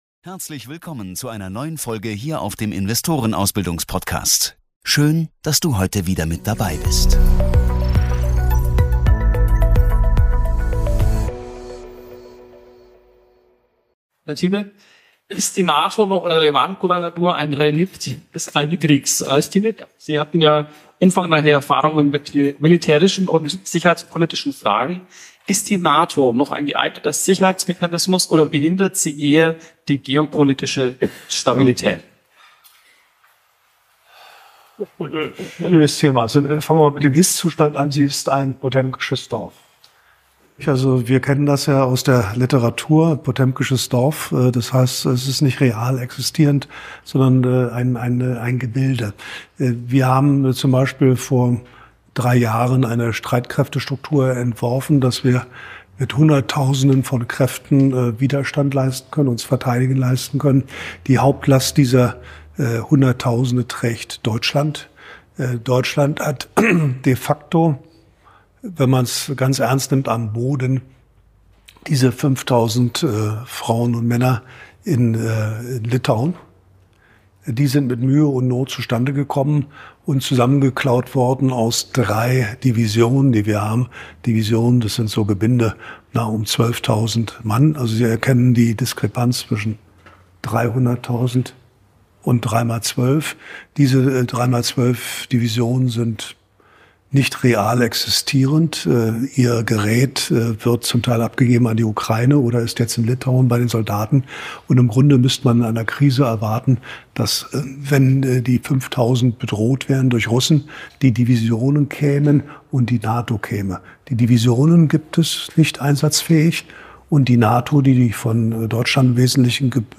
In dieser brisanten Diskussion
Die beiden Experten geben tiefe Einblicke in geopolitische Machtverschiebungen und die wachsenden Spannungen zwischen USA, Europa und China. Eine schonungslose Analyse der aktuellen Weltlage und Europas Position darin.